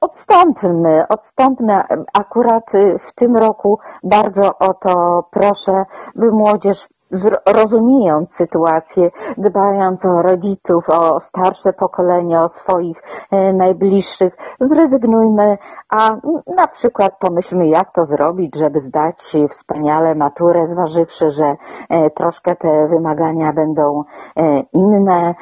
ROZMOWA DNIA
Magdalena Zarębska-Kulesza była Gościem w Rozmowie Dnia.